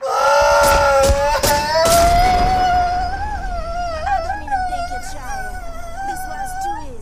Shiv Cry
shiv-cry.mp3